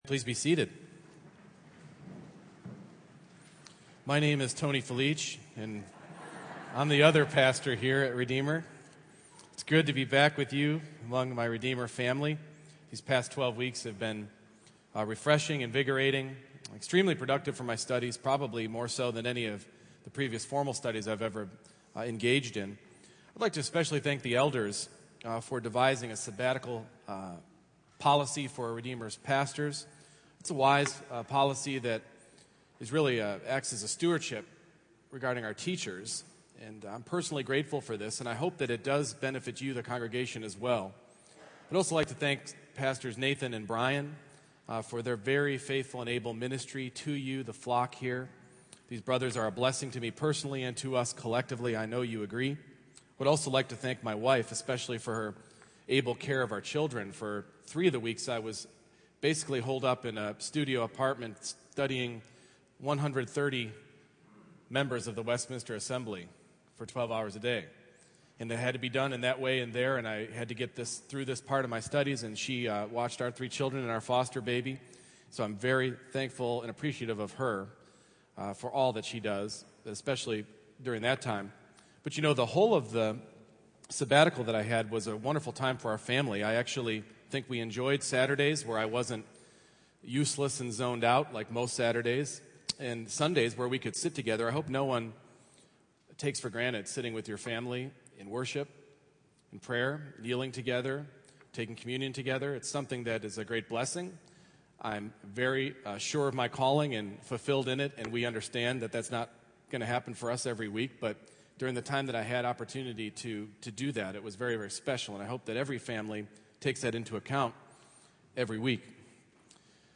Philippians 3:1-8 Service Type: Morning Worship Where is your confidence for life and eternity?